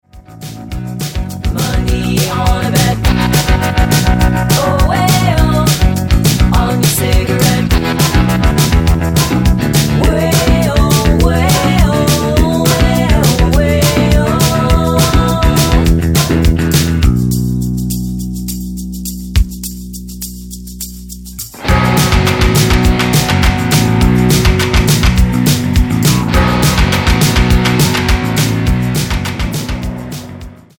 Tonart:B mit Chor